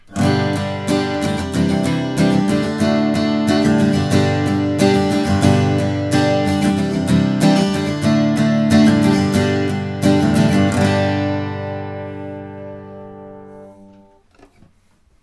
He made under fixed conditions samples of each guitar.
Rythm 2
The warmer sound is from the 12 fret Dreadnought.